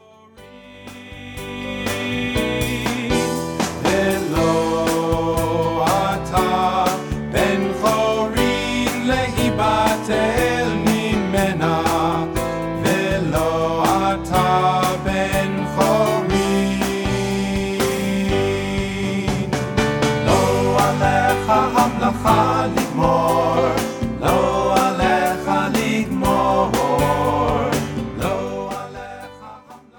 giving their music a uniquely modern sound.